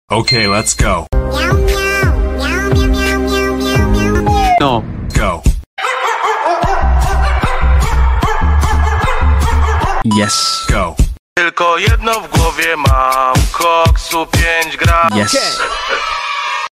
Cow, Poor Cat Vs Dog Sound Effects Free Download